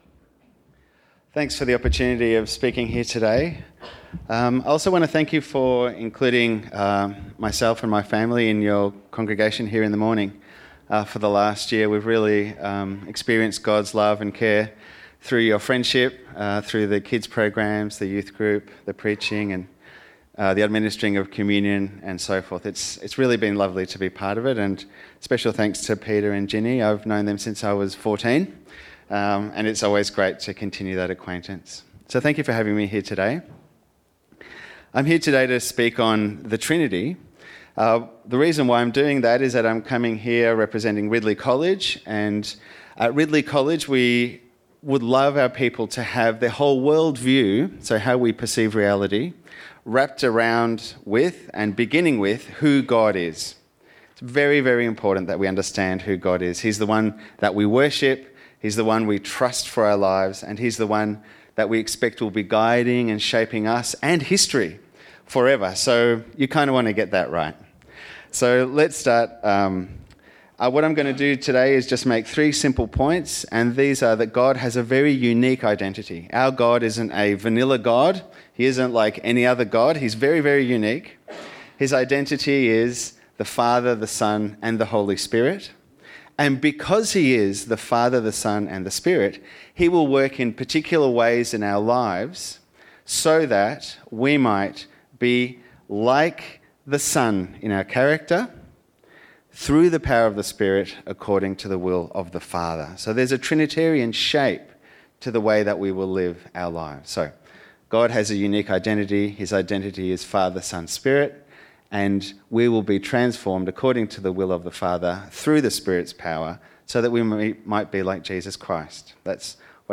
Sermons | St Alfred's Anglican Church
Bible Passage